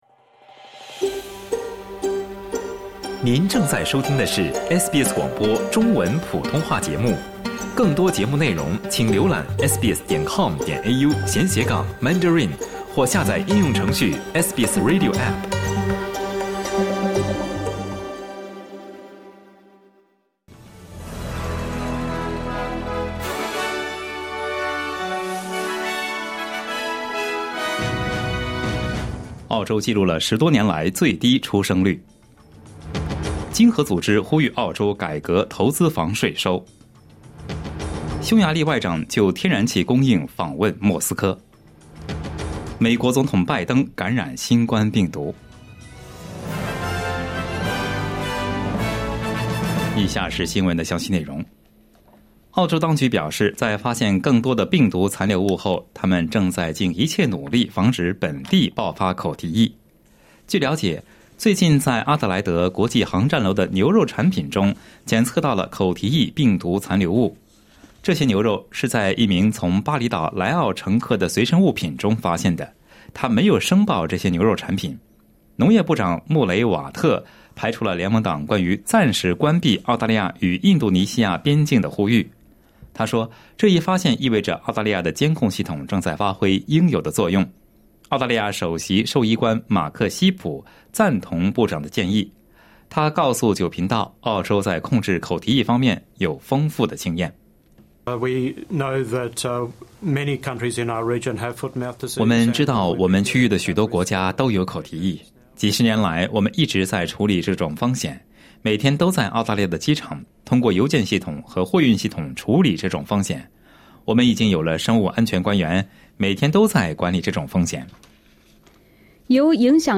SBS早新闻（7月22日）